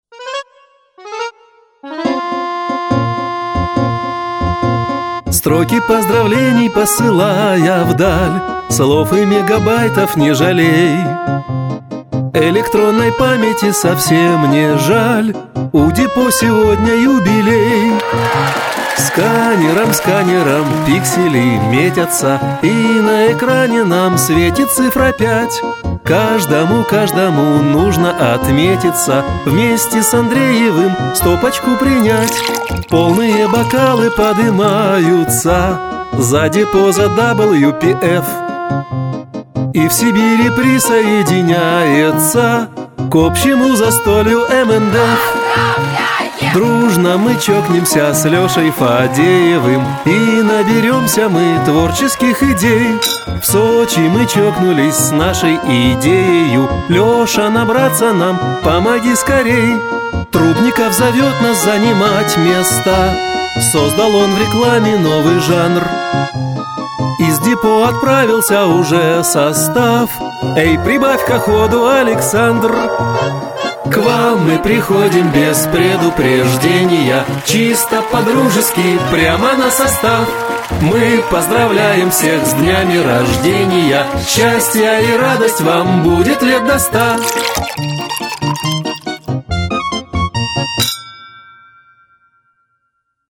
Музыкальное поздравление от РА "Мелехов & Филюрин" (MP3 1,42Mb)